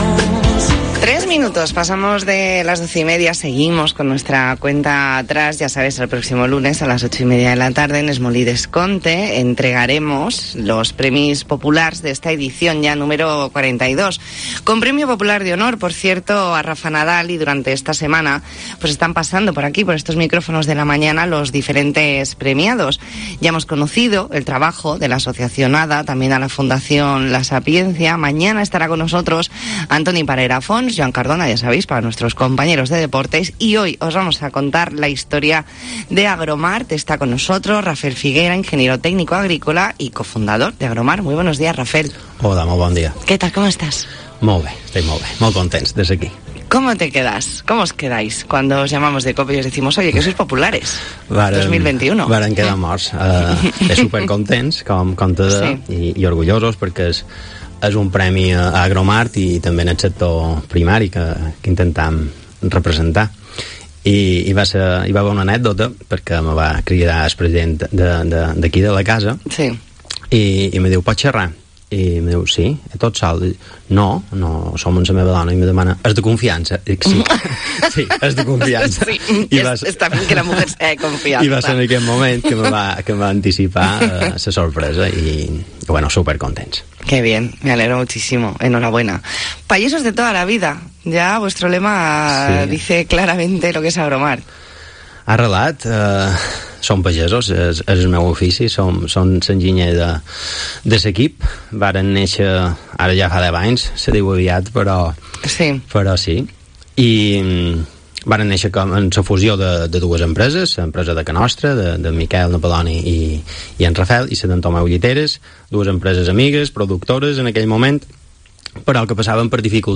Entrevista en La Mañana en COPE Más Mallorca, miércoles 24 de noviembre de 2021.